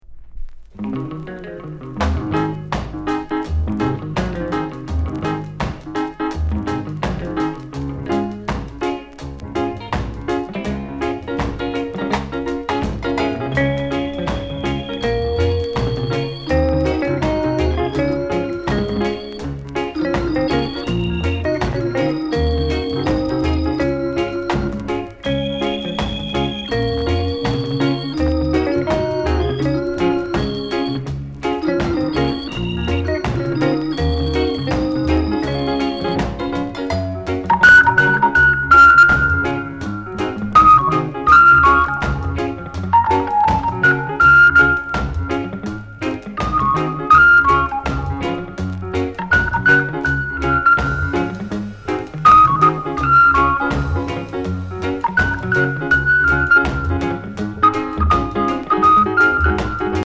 NICE ROCKSTEADY INST